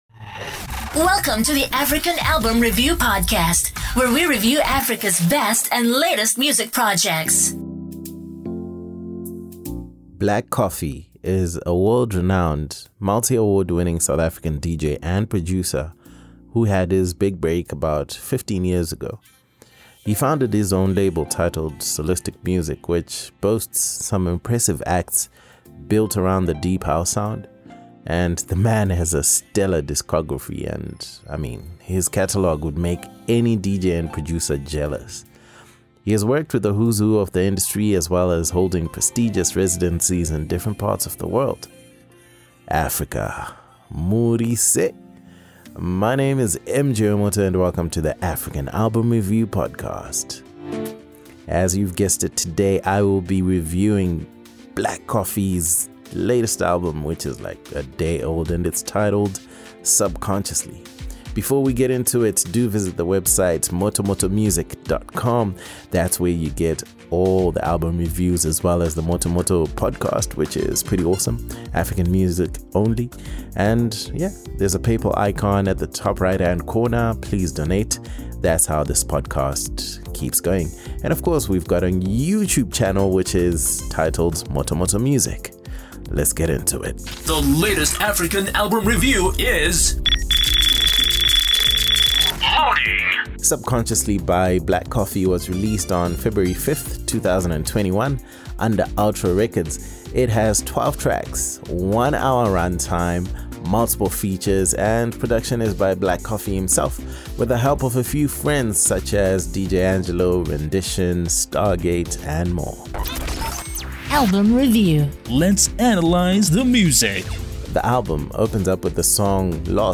Black Coffee – Subconsciously ALBUM REVIEW South Africa ~ African Album Review Podcast